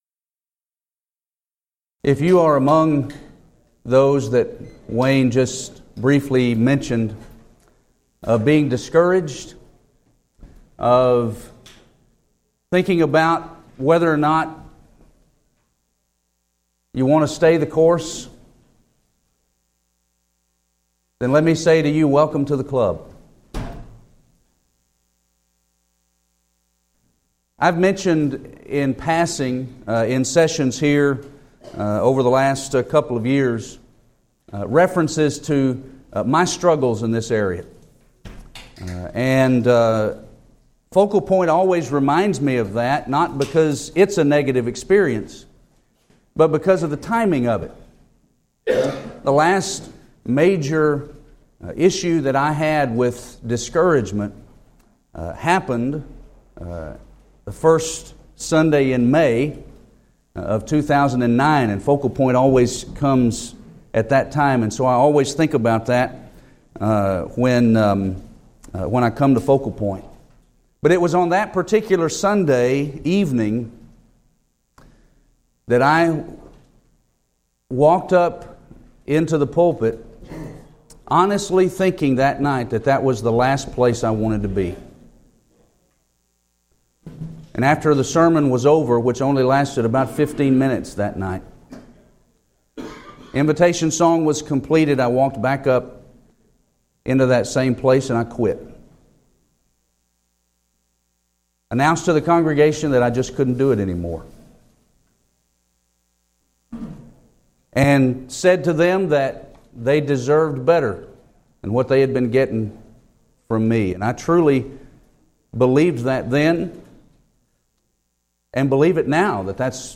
Preacher's Workshop
lecture